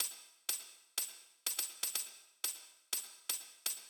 ENE Beat - Perc Mix 3.wav